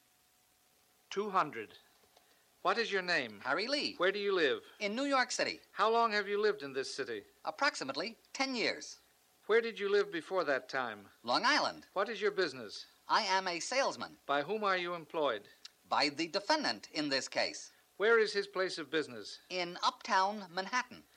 To my surprise, I discovered nearly 70 LPs, each holding a treasure trove of more than 400 dictations in various speeds and topics, including material written in Gregg shorthand.
200 Testimony
It took us several months to carefully extract the dictations from the LPs, convert them into a digital format, and upload them into the NCRA Learning Center.